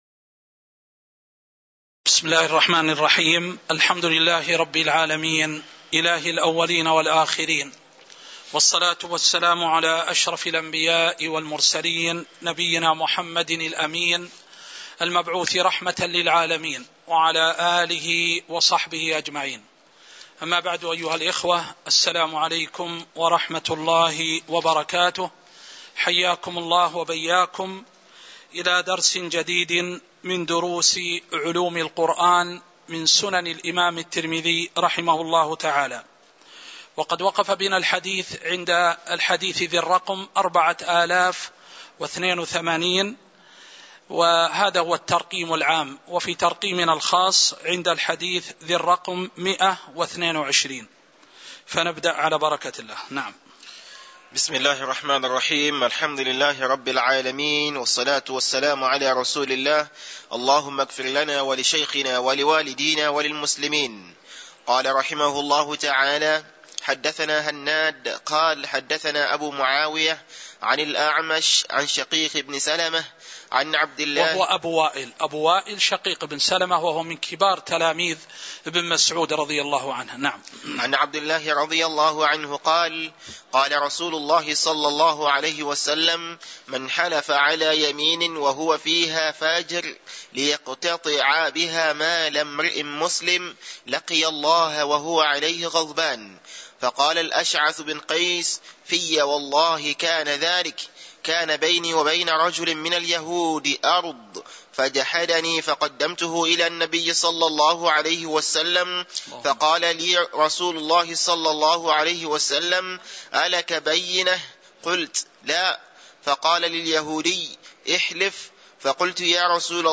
تاريخ النشر ٣ ربيع الأول ١٤٤٣ هـ المكان: المسجد النبوي الشيخ